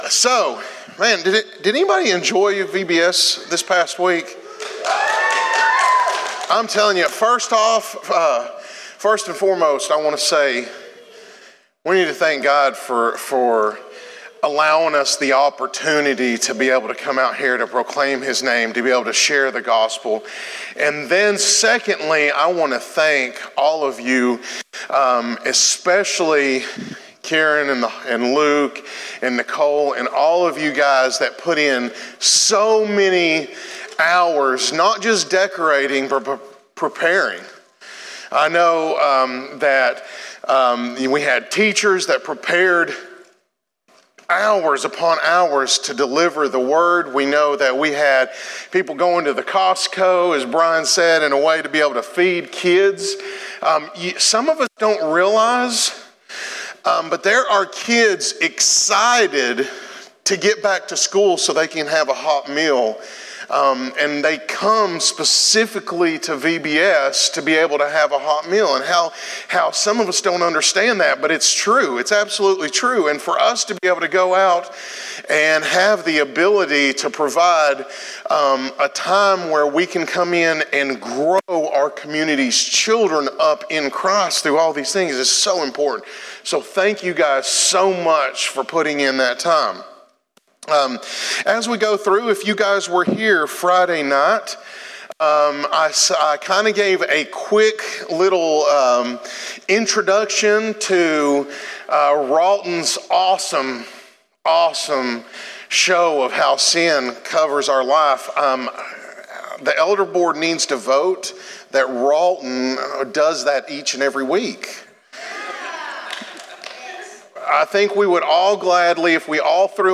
sermon.cfm